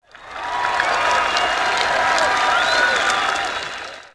crowd.wav